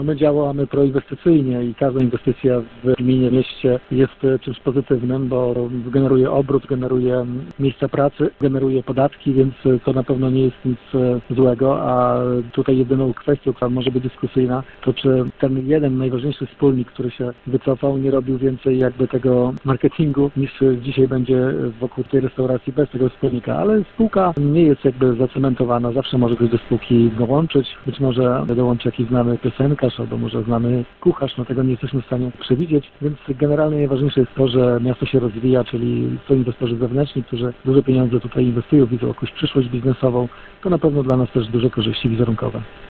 Włodarz grodu nad Niegocinem mówi o korzyściach z tej inwestycji dla całego miasta.